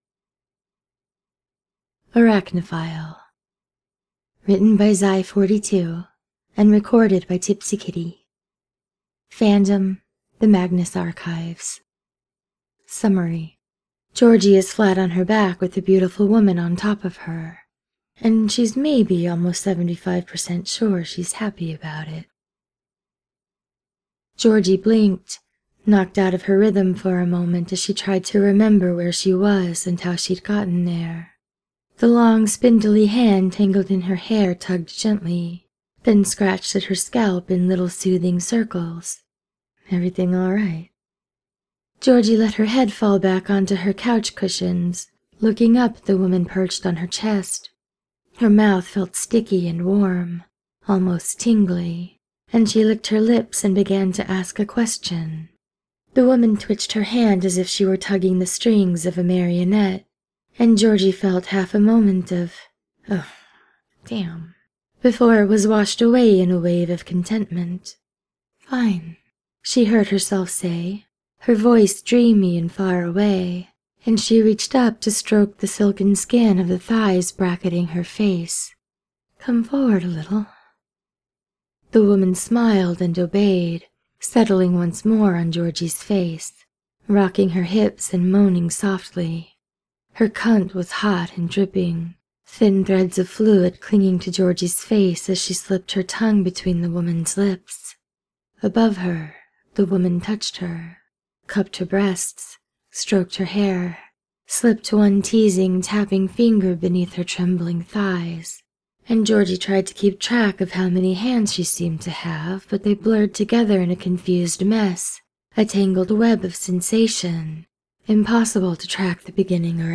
without music: